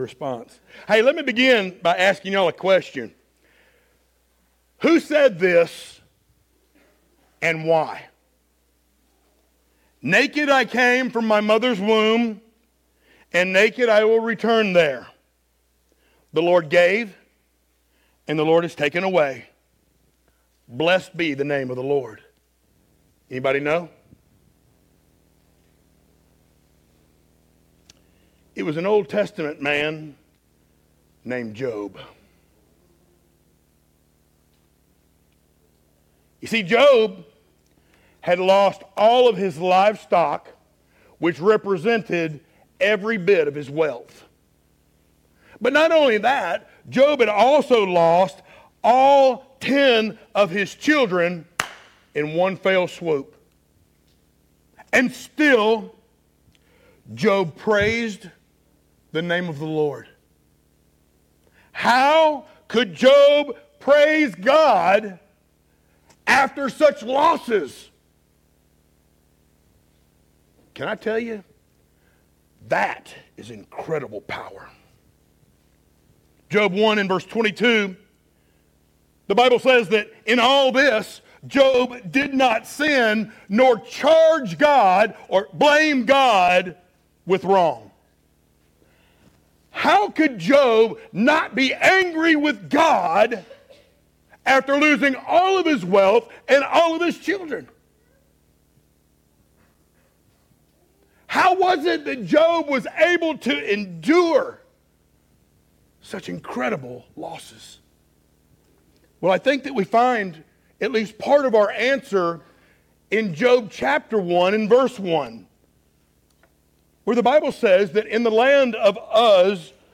Series: sermons
Ephesians 3:14-21 Service Type: Sunday Morning Download Files Notes Topics